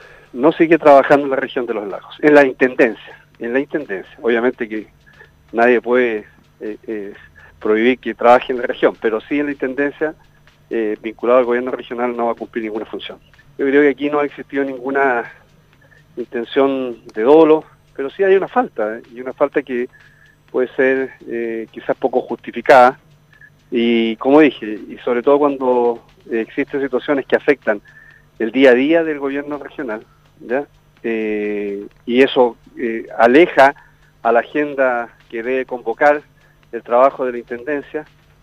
El parlamentario entregó la información en el programa Haciendo Ciudad de Radio Sago, agregando que si bien -a juicio del congresista- no existió intención de dolo en esta situación, sí existió una falta en la que era necesario tomar las respectivas medidas.